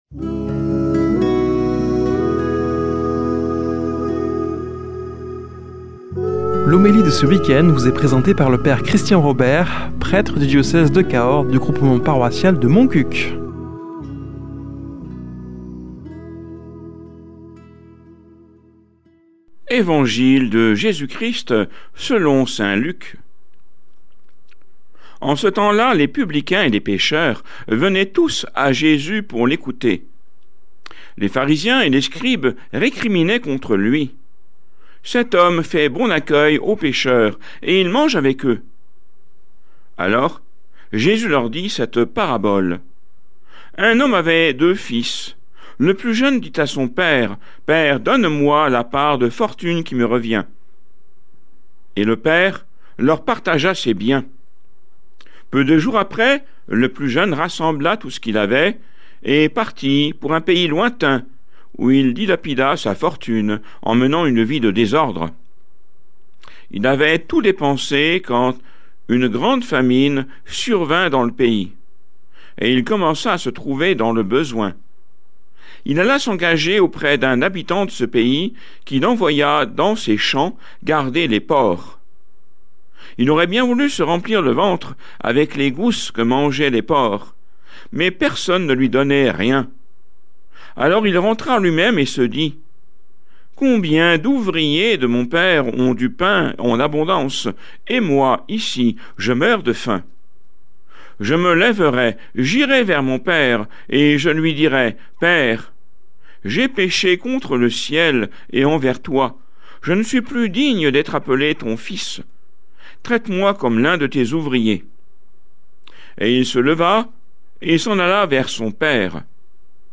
Homélie du 29 mars